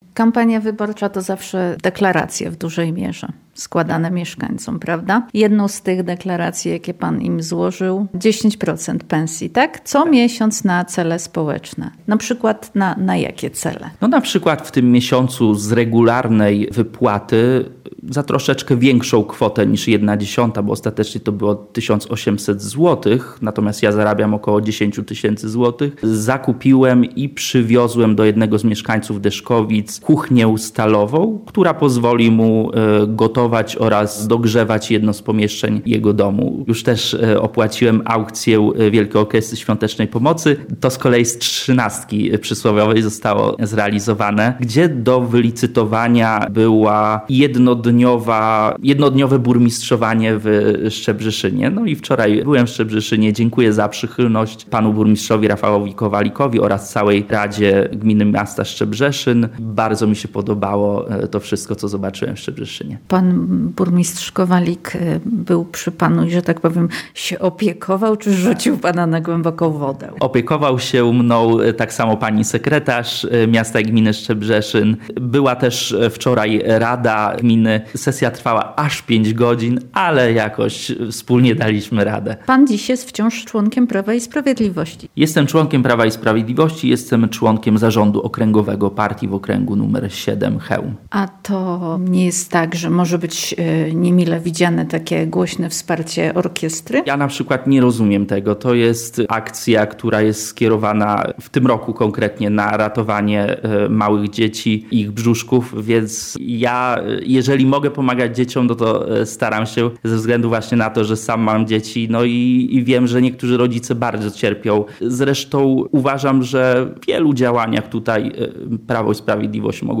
Rozmowa z wójtem Sułowa - Piotrem Kaliszewskim